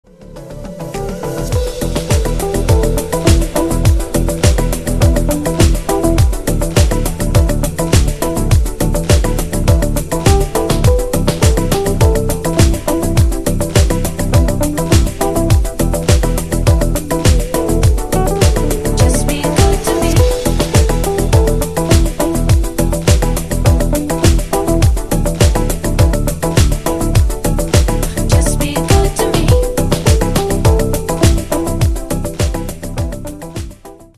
Oldies